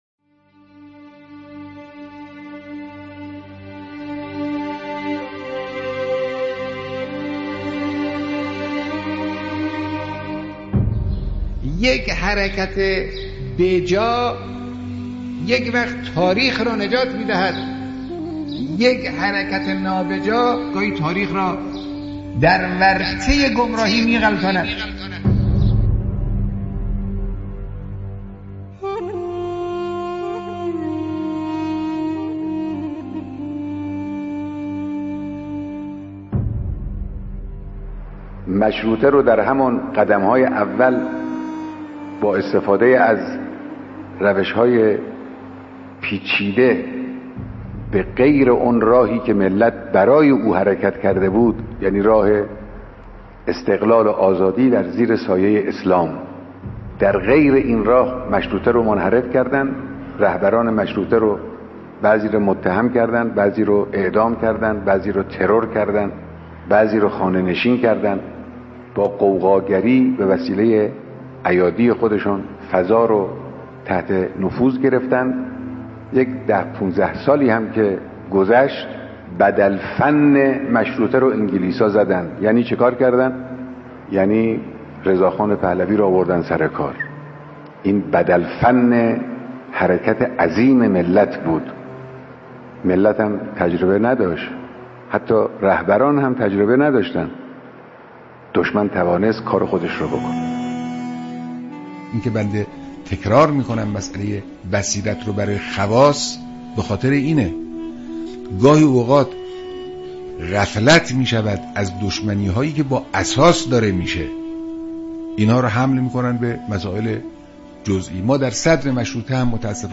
صوت/ بیانات رهبر معظم انقلاب اسلامی به مناسبت سالگرد شهادت شیخ فضل‌الله نوری